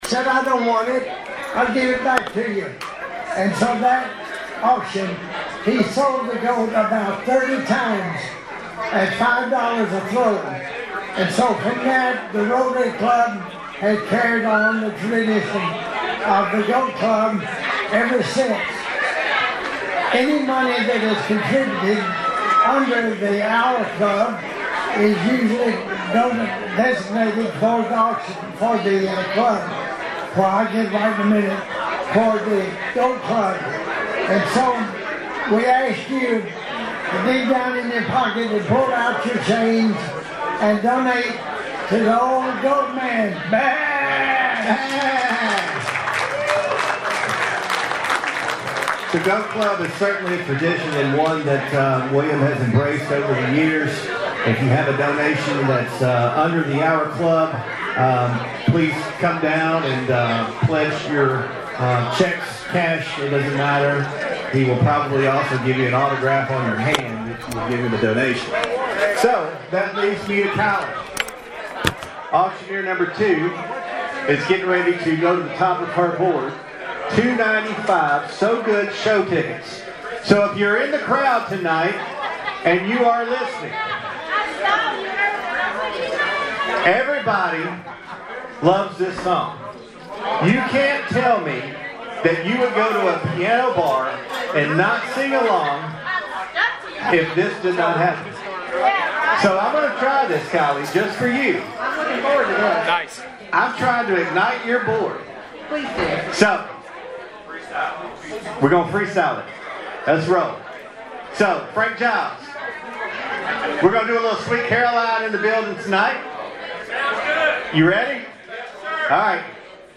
Always looking to raise “one more dollar than Cadiz,” Wednesday night’s efforts at the 75th Annual Hopkinsville Rotary Auction inside the War Memorial Building helped the club move well past $200,000 in fundraising.